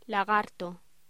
Locución: Lagarto
voz